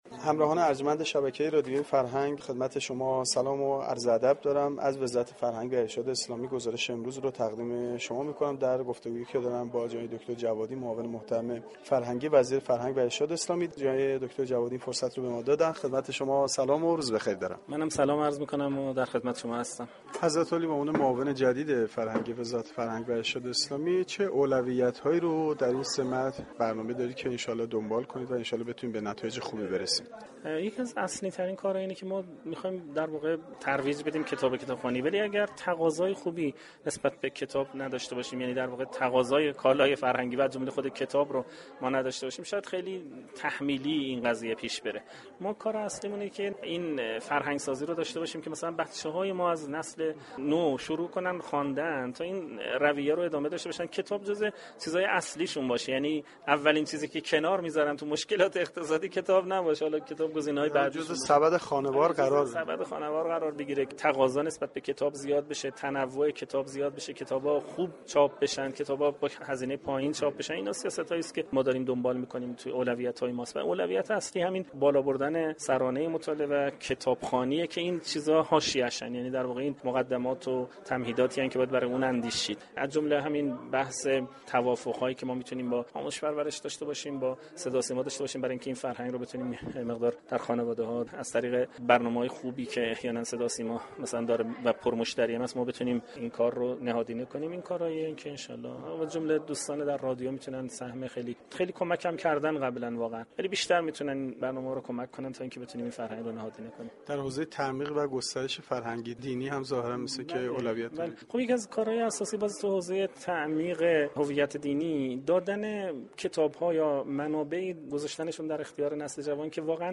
محسن جوادی معاون فرهنگی وزیر فرهنگ و ارشاد اسلامی در گفتگو با گزارشگر رادیو فرهنگ ،بالابردن سرانه ی مطالعه و كتابخوانی را جزو اولویتهای اصلی و مهم معاونت فرهنگی وزارت فرهنگ و ارشاد اسلامی دانست